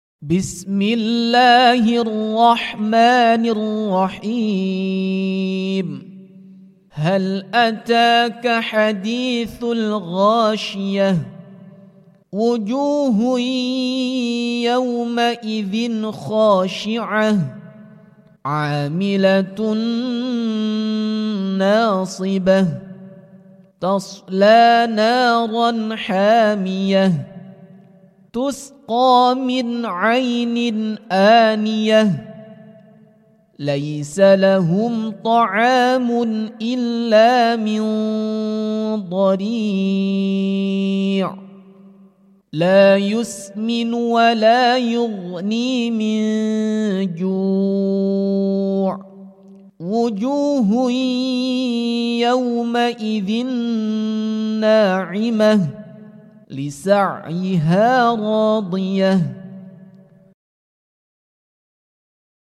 🎬 Murottal Surah Al-Ghasyiyah [Full] Irama Rost 🎙Qiroah